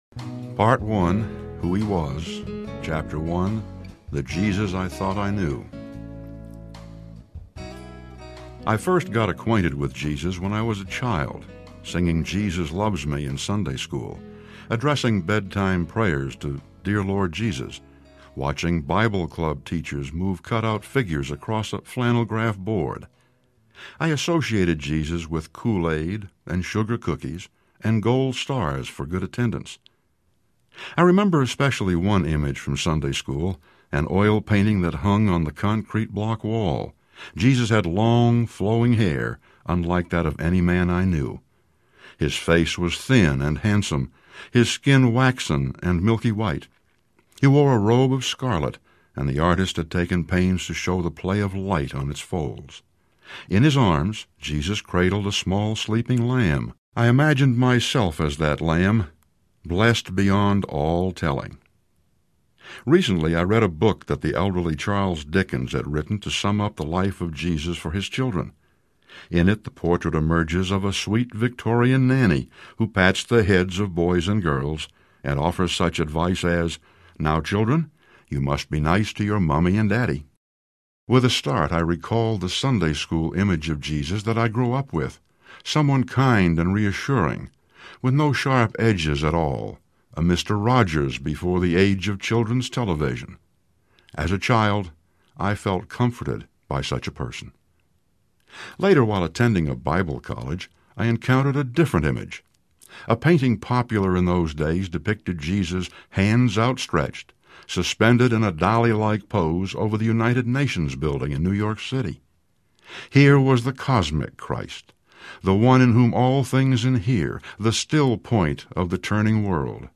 The Jesus I Never Knew Audiobook
Narrator
9.6 Hrs. – Unabridged